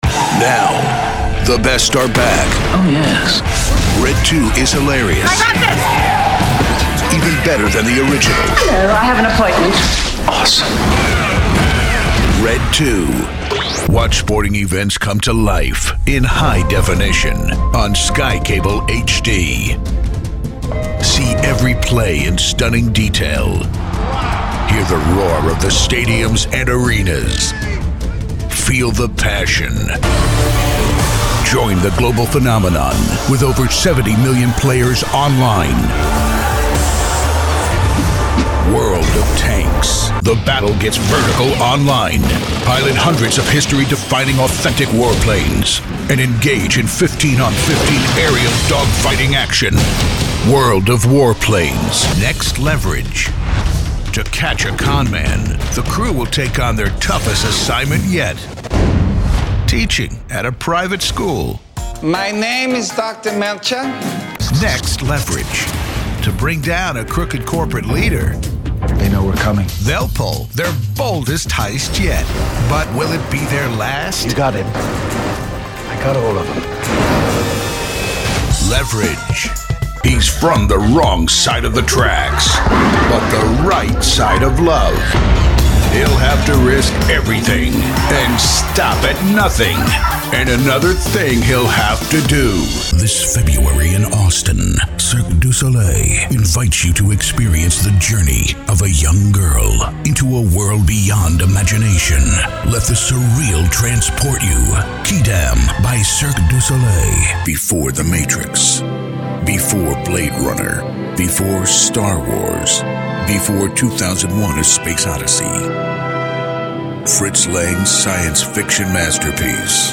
Deep, Epic, Motivational.
Movie Trailer